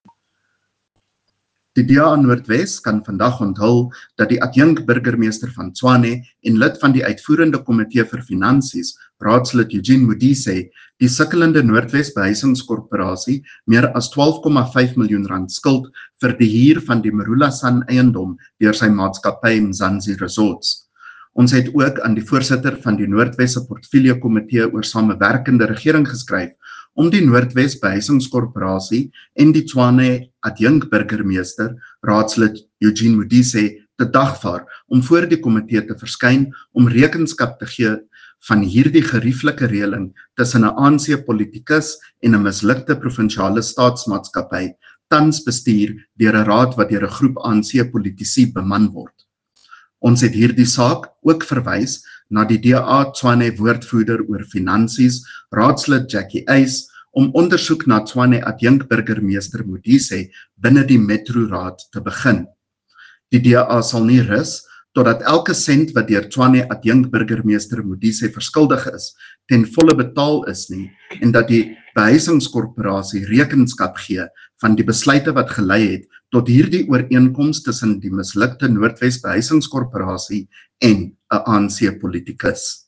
Note to Broadcasters: Please find linked soundbites in English and